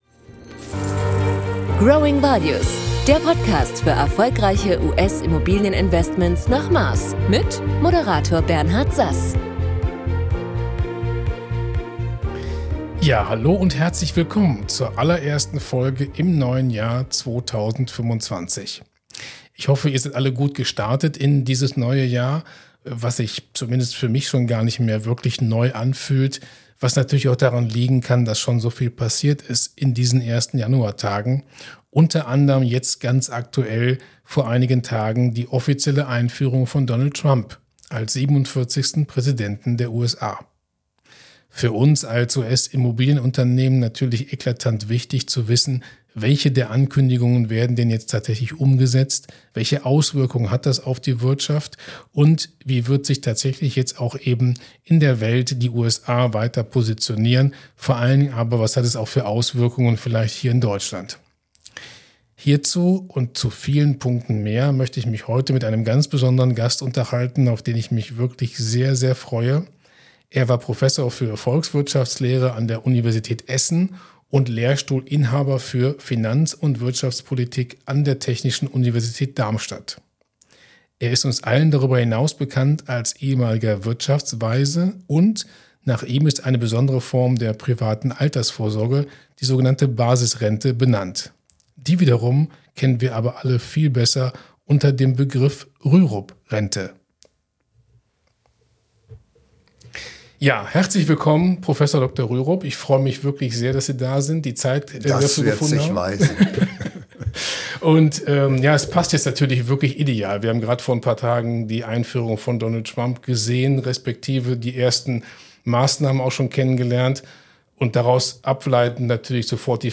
In unserer ersten Folge im neuen Jahr 2025 durften wir direkt zum Start einen hochkarätigen Interviewgast in unserem kleinen Podcast-Studio in Düsseldorf begrüßen: Prof. em. Dr. Dr. h.c. Bert Rürup.